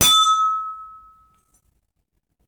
Glass
Ding Glass Impact Kitchen Ring Ting sound effect free sound royalty free Sound Effects